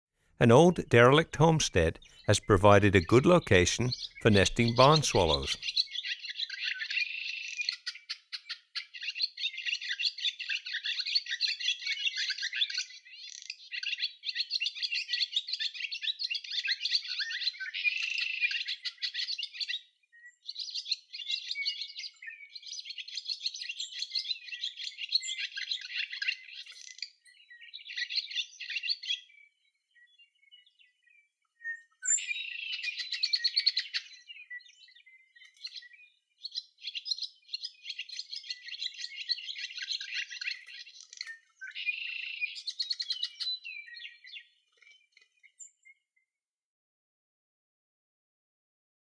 Barn Swallow’s Song
63-barn-swallow.m4a